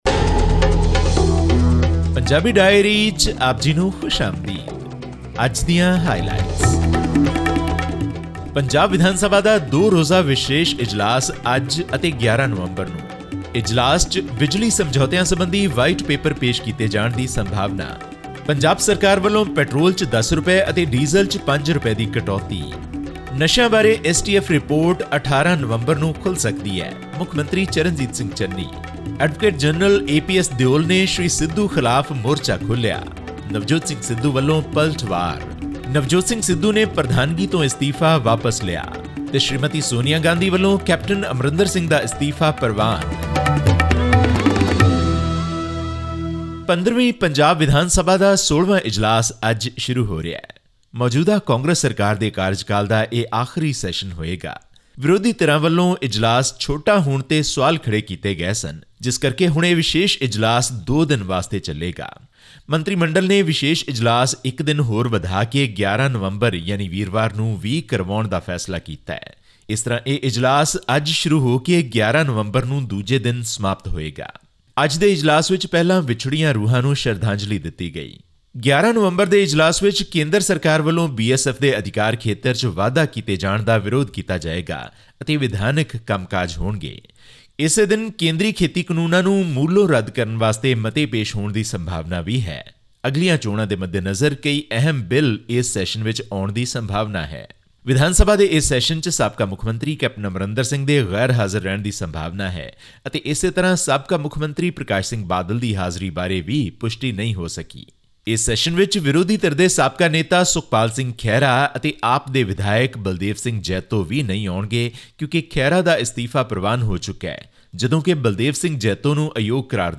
Punjab Congress chief Navjot Singh Sidhu met party leader Rahul Gandhi on 5 November and withdrew his exit notice saying all his issues have been resolved. All this and more in our weekly news update from Punjab.